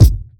Kick33.wav